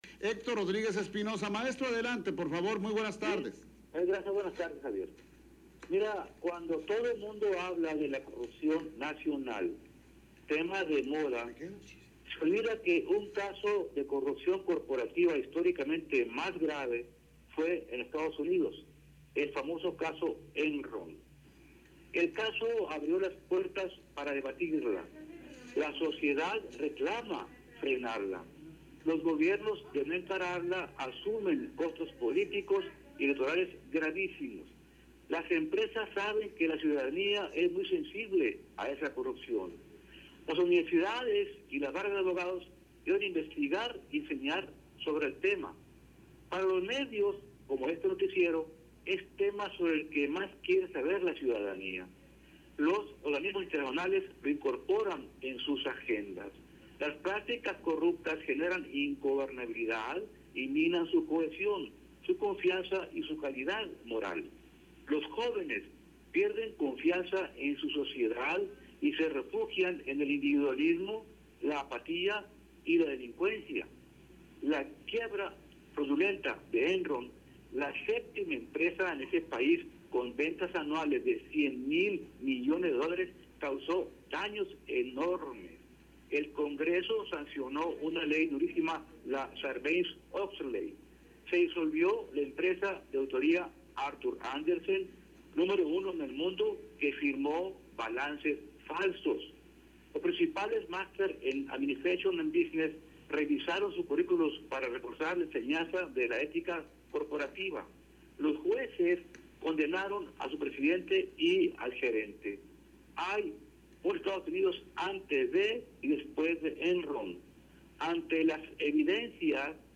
(LARSA, 88.9FM).